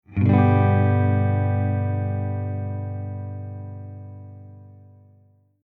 They are three tones or more played together at the same time.
G Chord
gchord.mp3